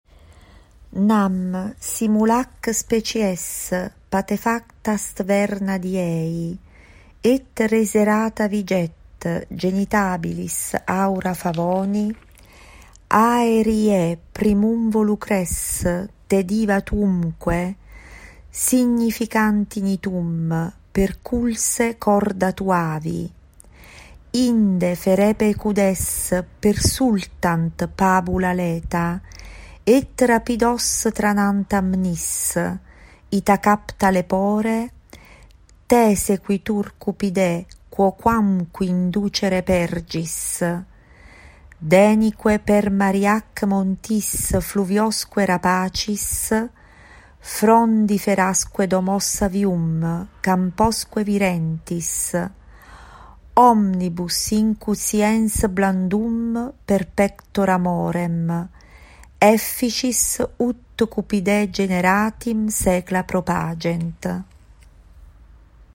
Ecco la lettura di questi bellissimi versi dell’Inno a Venere dal De rerum natura di Lucrezio da parte dei nostri soci filologi e classicisti